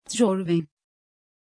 Aussprache von Tjorven
pronunciation-tjorven-tr.mp3